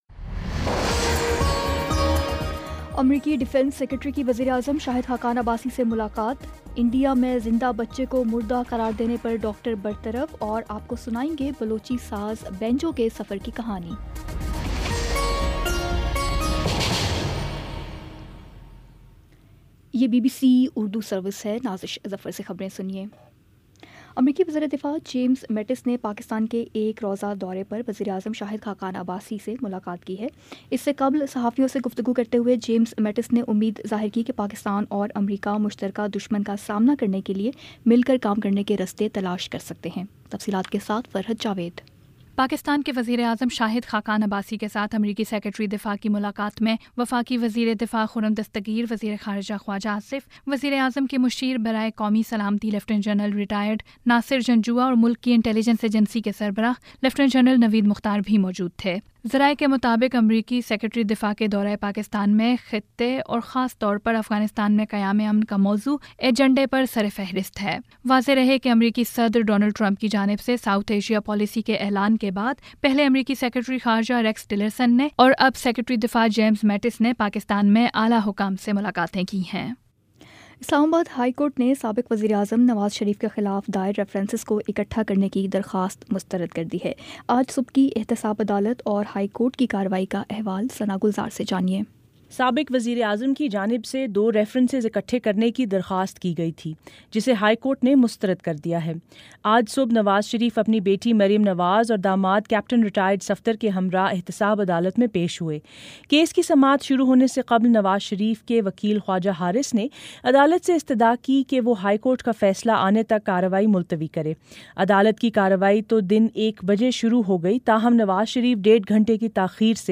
دسمبر 04 : شام پانچ بجے کا نیوز بُلیٹن